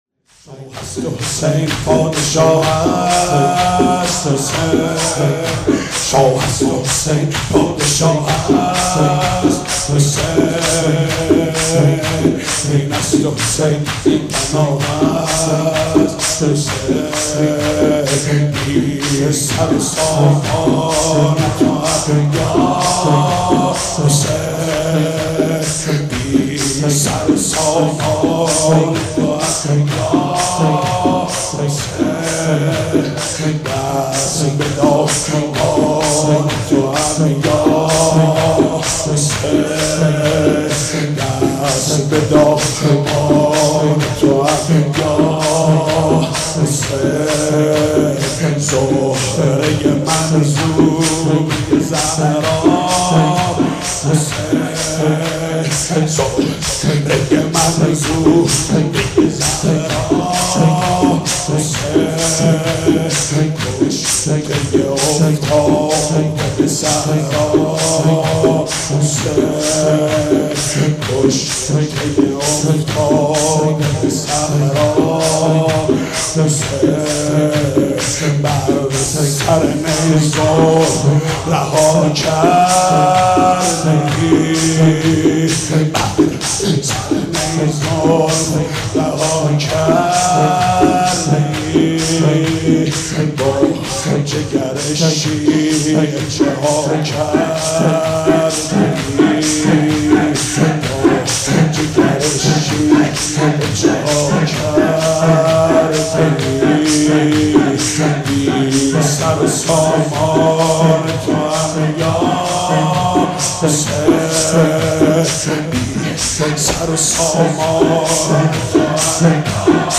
نوای محزون «شاه است حسین، پادشاه است حسین» را با صدای حاج محمود کریمی می‌شنوید